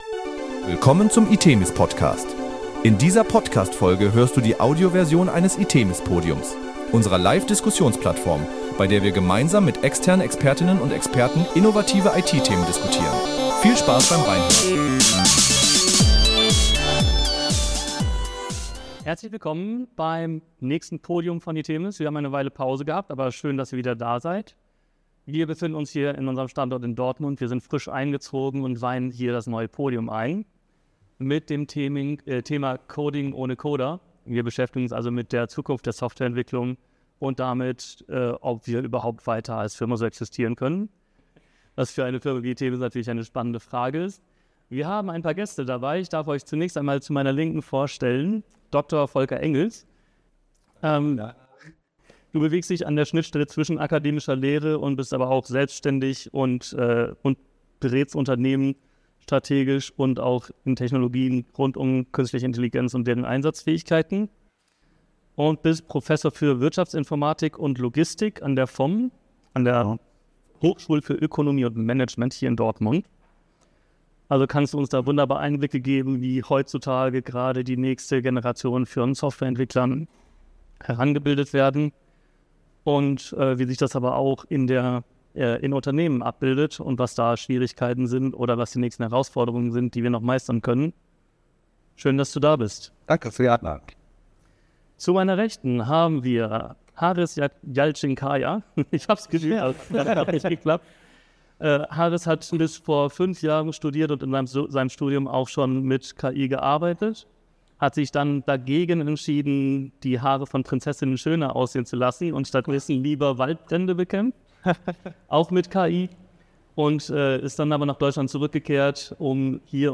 Herzlich willkommen zu einer spannenden Diskussion über die Zukunft der Softwareentwicklung!